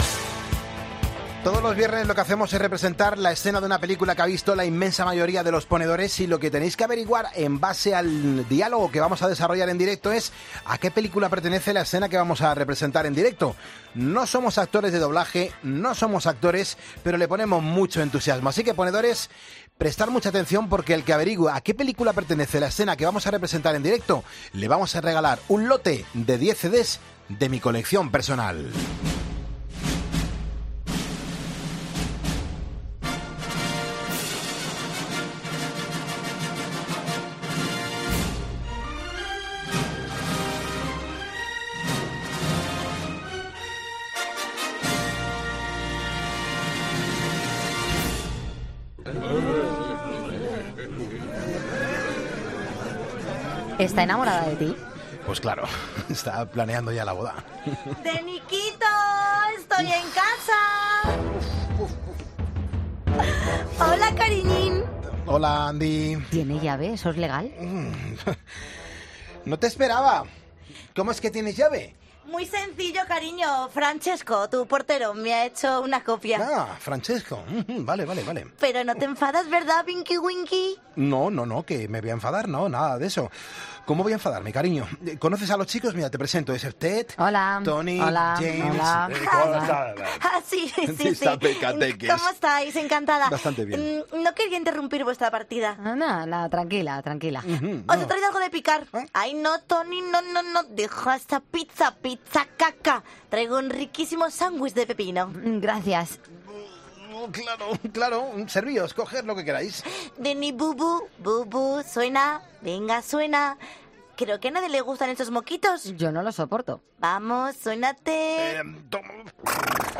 Cada viernes nos hacemos pasar por actores de doblaje y nos echamos unas risas y, oye, creemos que vosotros también, Ponedores.